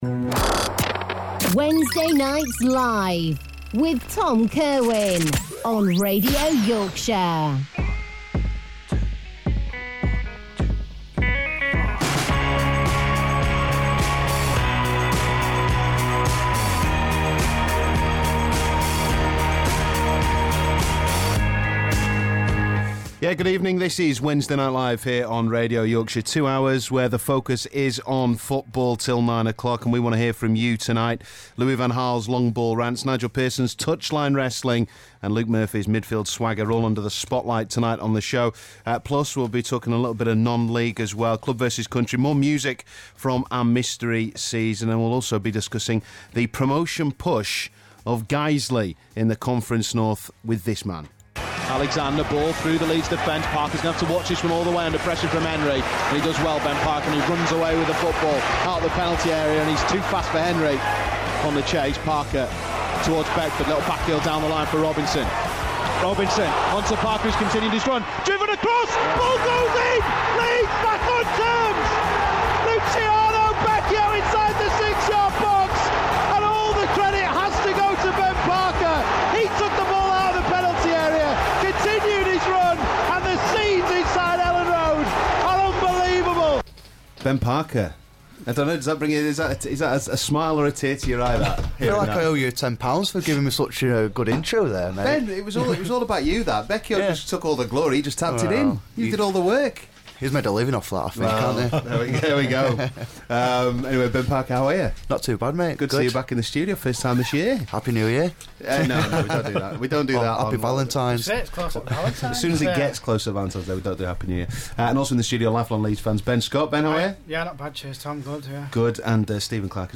along with fans to talk Leeds United and Premier League rights.